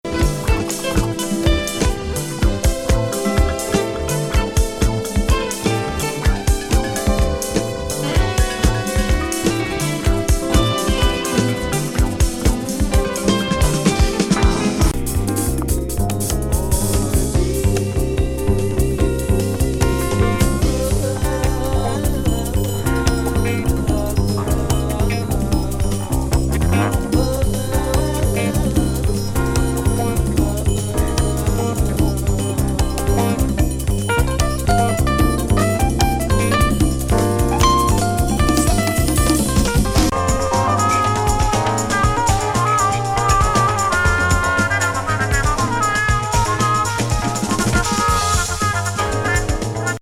南部ファンキー